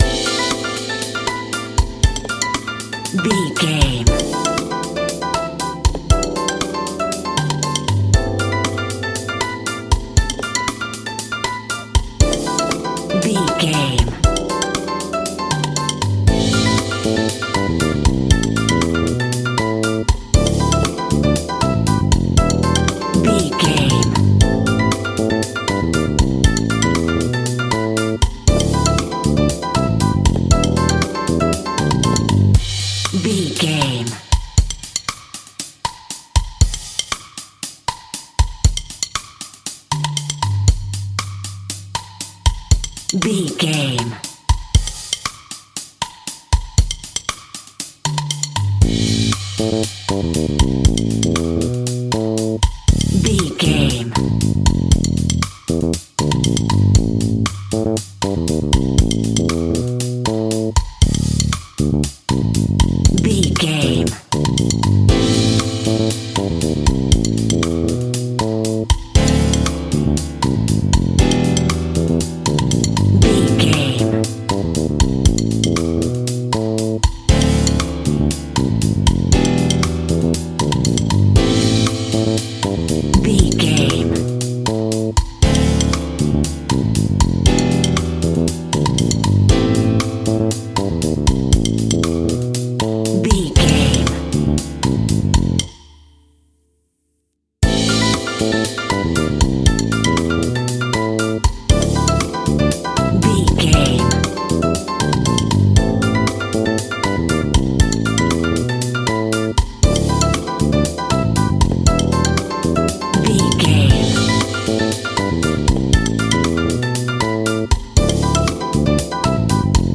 A cool infusion of Cuba and Rhumba music!
Exotic, spicy and from another world!
Aeolian/Minor
salsa
World Music
drums
bass guitar
electric guitar
piano
hammond organ
percussion